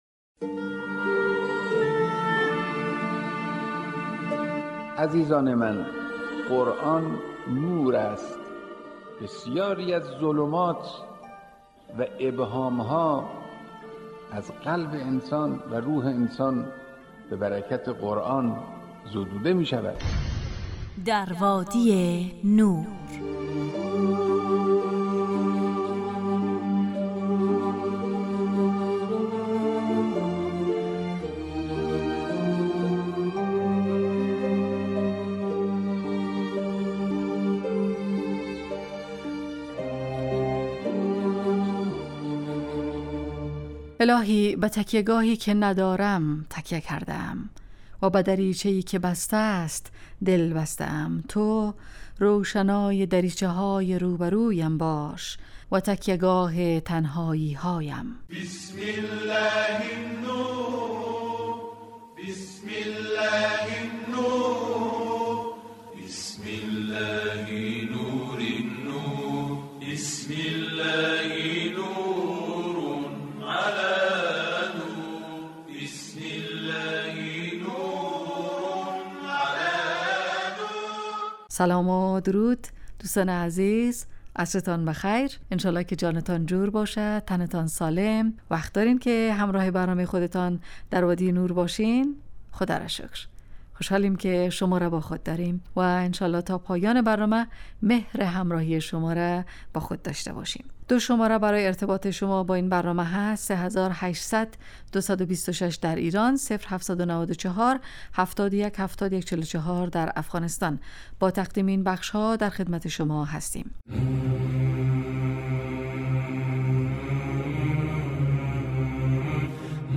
طلایه داران تلاوت، ایستگاه تلاوت